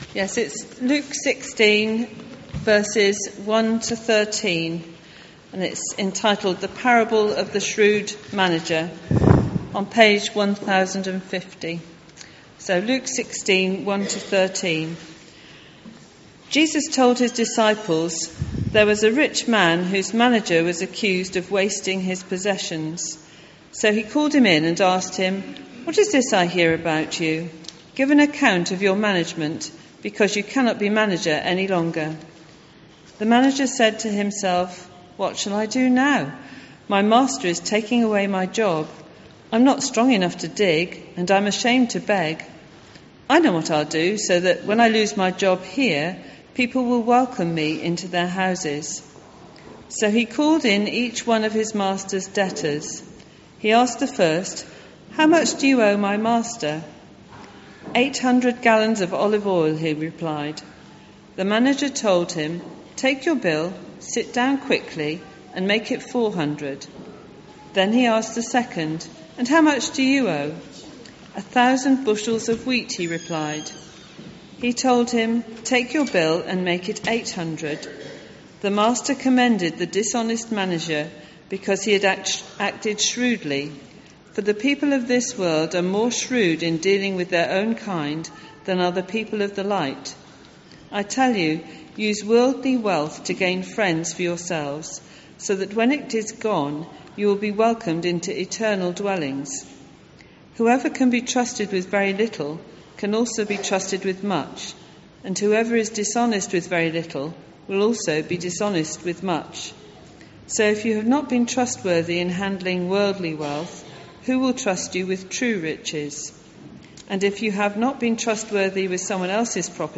14th October 2018 – All-age Harvest Service